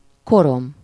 Ääntäminen
Ääntäminen France: IPA: /sɥi/ Haettu sana löytyi näillä lähdekielillä: ranska Käännös Ääninäyte 1. korom Suku: f .